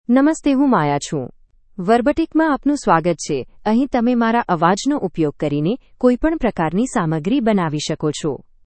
Maya — Female Gujarati (India) AI Voice | TTS, Voice Cloning & Video | Verbatik AI
MayaFemale Gujarati AI voice
Maya is a female AI voice for Gujarati (India).
Voice sample
Listen to Maya's female Gujarati voice.
Maya delivers clear pronunciation with authentic India Gujarati intonation, making your content sound professionally produced.